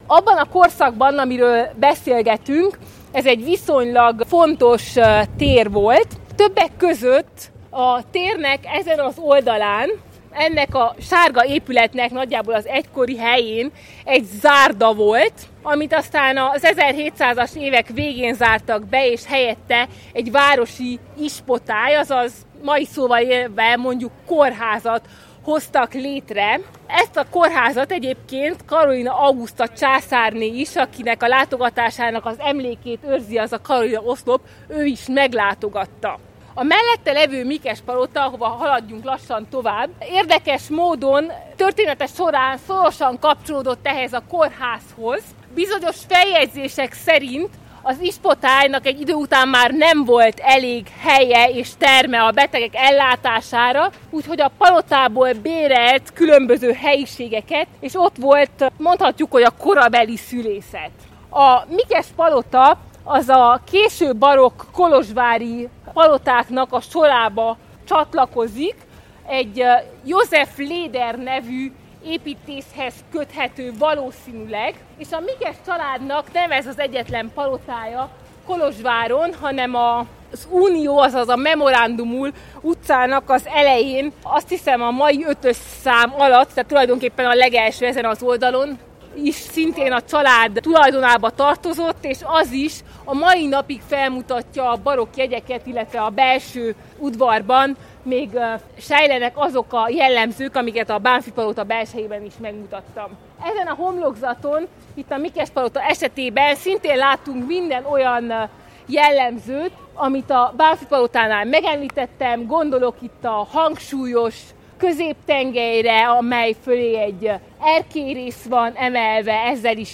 Idén három, különböző korokat érintő sétán vettünk részt, összeállításunkba ezekből válogattunk részleteket.
A mi részletünk viszont a Karolina térről származik, ahol a Mikes-palotán figyelhetők meg legjobban a barokk jegyek, emellett a Karolina-oszlopot is körbejárjuk, a maga érdekességeivel.